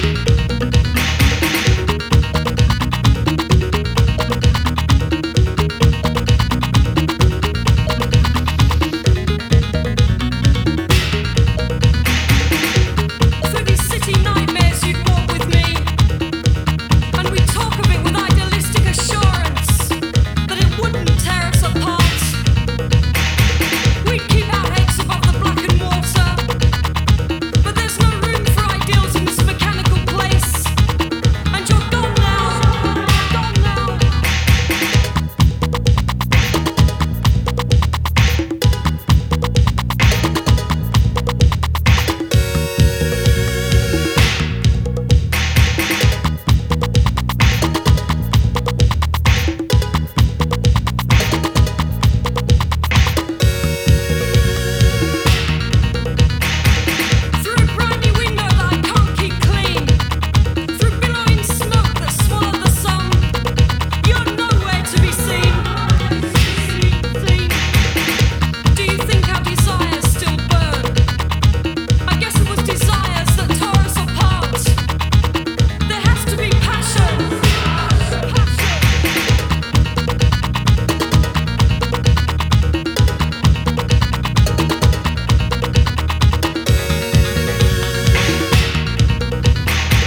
proto-house
ritmos hidráulicos y arpegios sombríos